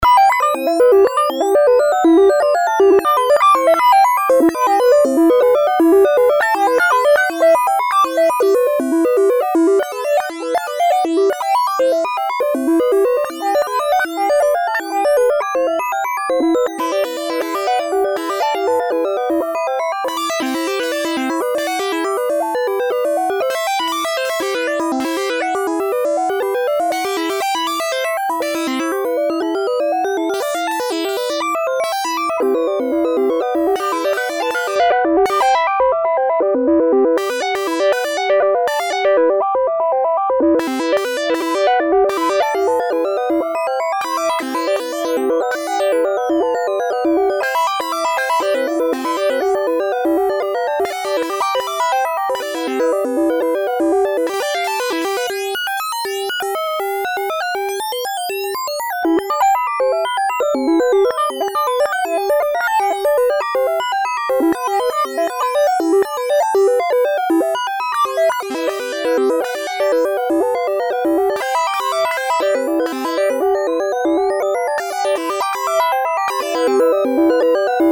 forlorn playhous - i think this was about divesting rhythm from melody in terms of sequencing and sequence length (i think melody is also at least three sequences stacked) which we can then put some drums on to make: tamed forlorn playhous